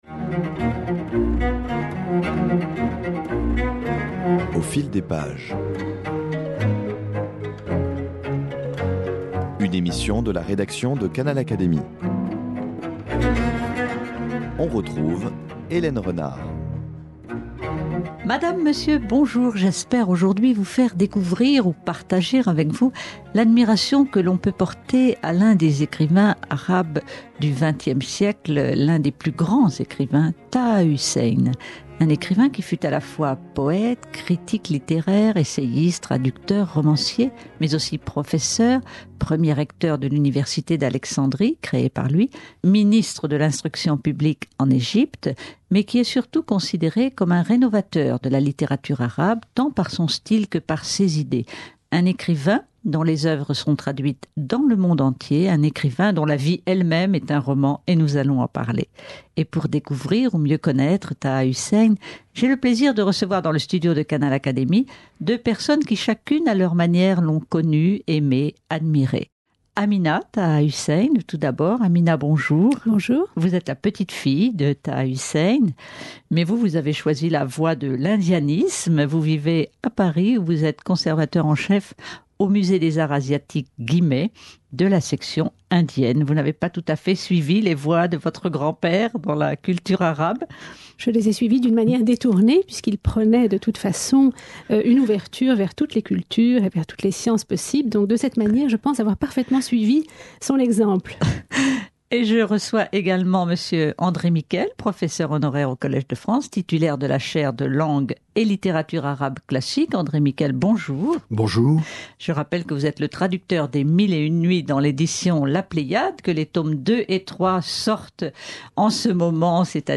Plusieurs lectures de ces écrivains sont ici proposées : - lecture d'un extrait de Maalesh, journal d'une tournée de théâtre de Jean Cocteau (éd.